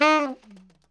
TNR SHFL D#4.wav